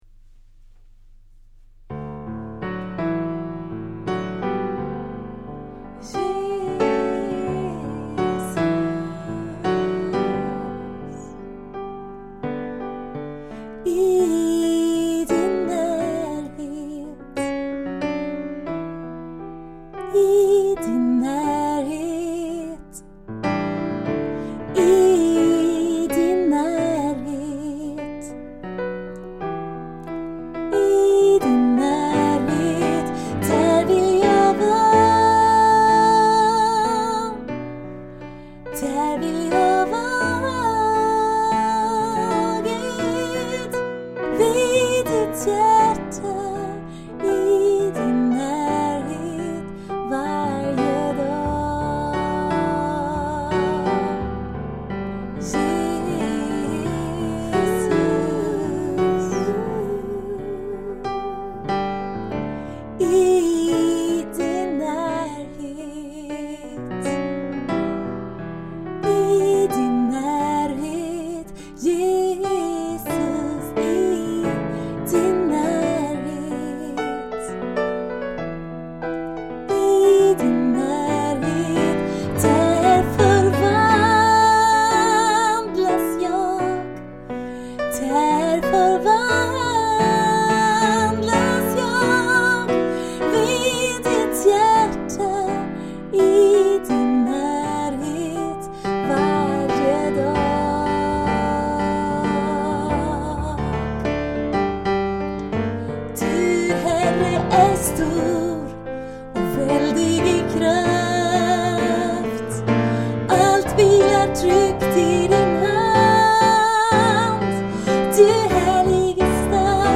Sånger för vila och stillhet och påfyllning i Guds närhet.
(ny enkel inspelning från 1 maj 2015)